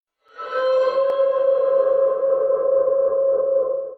REVERB OOF
reverb-oof.mp3